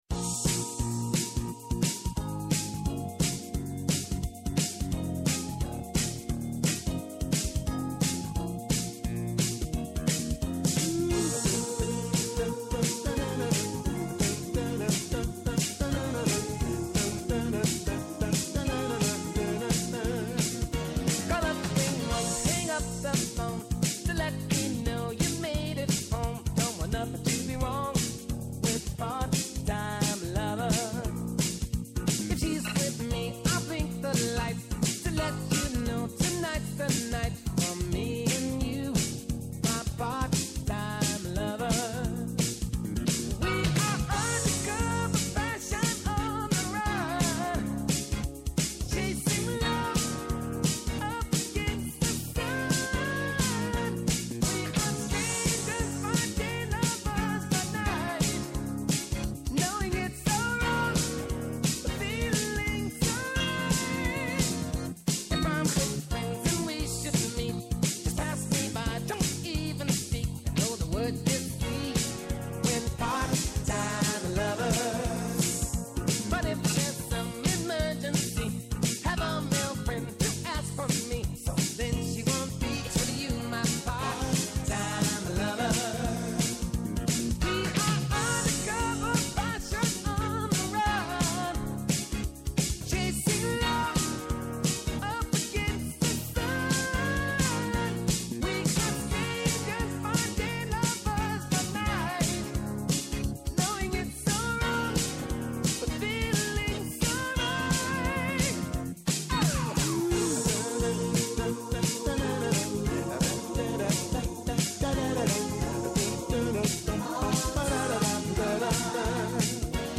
Με ζωντανά ρεπορτάζ από όλη την Ελλάδα, με συνεντεύξεις με τους πρωταγωνιστές της επικαιρότητας, με ειδήσεις από το παρασκήνιο, πιάνουν τιμόνι στην πρώτη γραμμή της επικαιρότητας. Για να μαθαίνετε πρώτοι και με εγκυρότητα όσα συμβαίνουν και όσα έρχονται τη μέρα που ξεκινά.